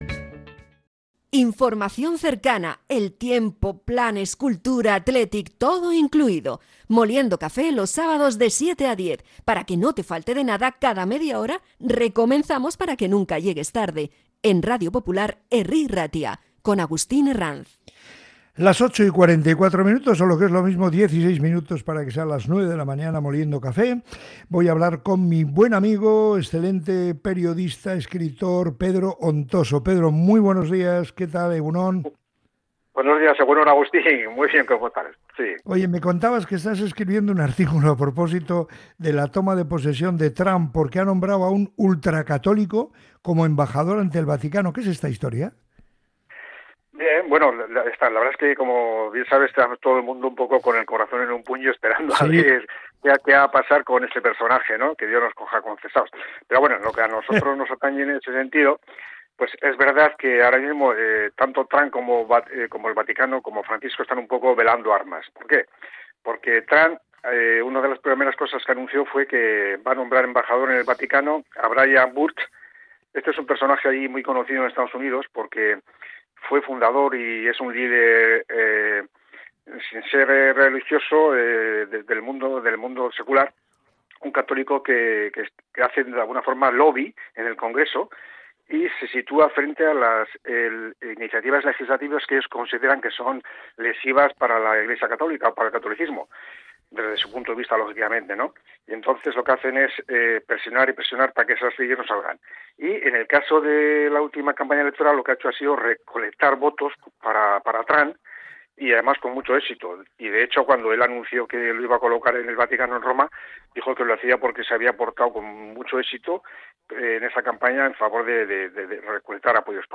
Finalmente, la conversación abordó la polémica en torno a las exmonjas de Belorado y su enfrentamiento con el Vaticano.